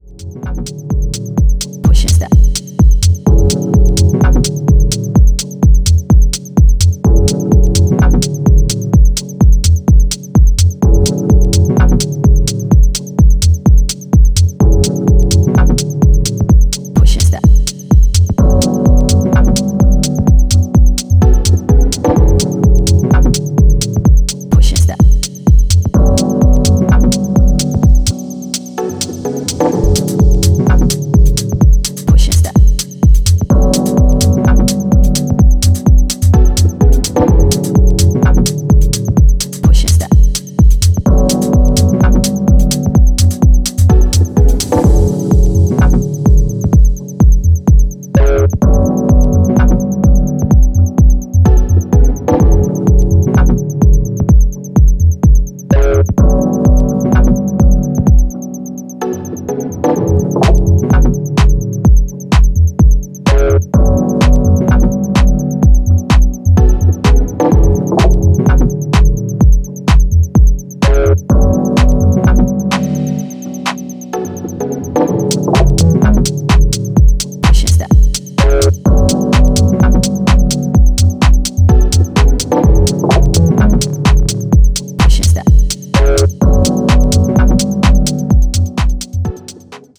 秀逸なミニマル・ハウス群を展開しています。